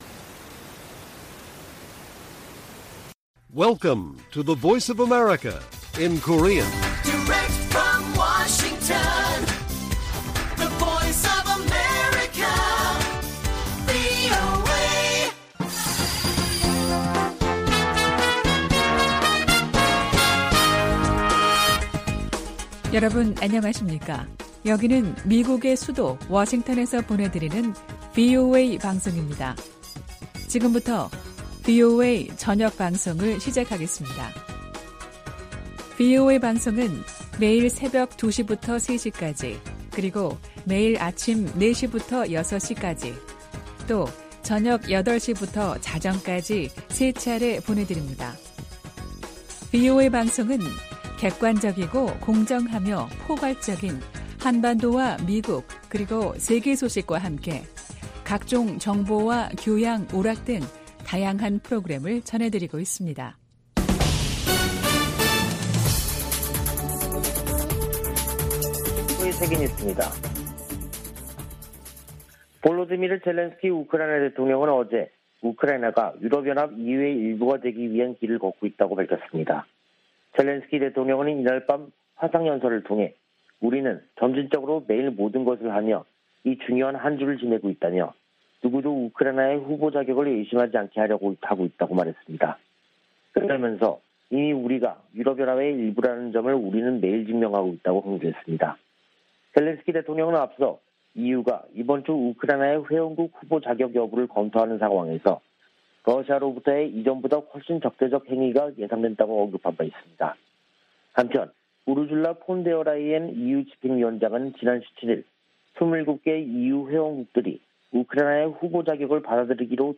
VOA 한국어 간판 뉴스 프로그램 '뉴스 투데이', 2022년 6월 21일 1부 방송입니다. 한국이 21일 자체 개발 위성을 쏘아올리는데 성공해, 세계 7번째 실용급 위성 발사국이 됐습니다. 북한이 풍계리 핵실험장 4번 갱도에서 핵실험을 하기 위해서는 수개월 걸릴 것이라고 미국 핵 전문가가 전망했습니다. 북한이 과거 기관총과 박격포 탄약, 수류탄 등 약 4천만개의 탄약을 중동 국가에 판매하려던 정황이 확인됐습니다.